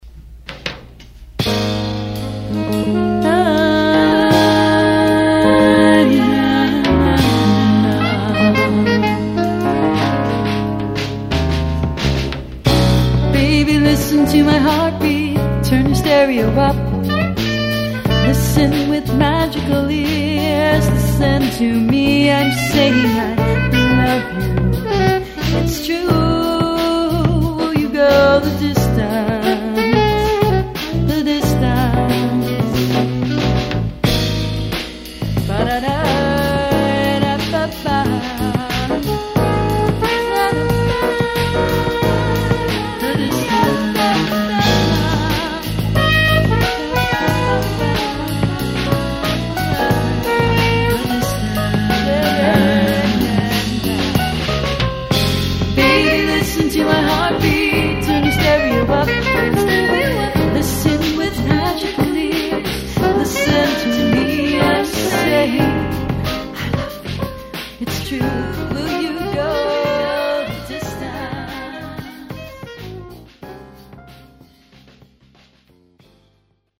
vox
sax
flugelhorn
bass\drums
on 4 track tape deck